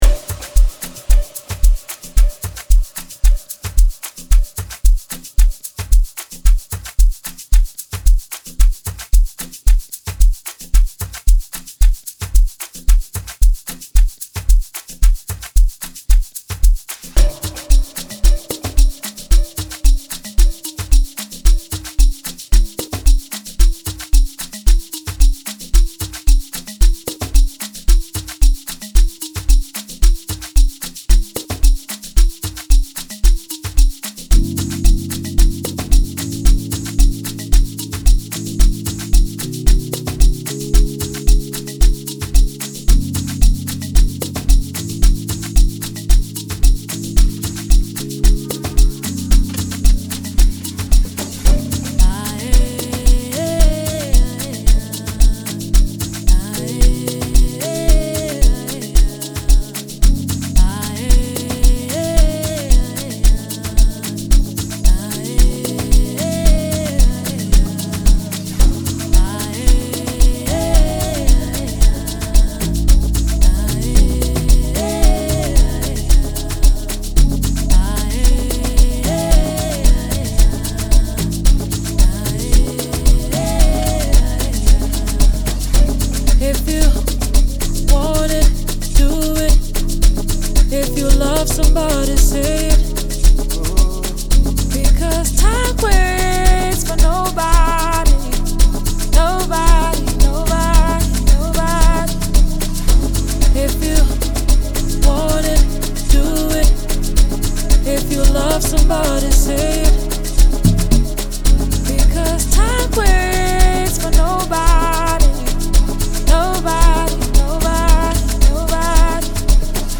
2024 Categoria: Amapiano Download RECOMENDAÇÕES